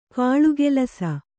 ♪ kāḷugelasa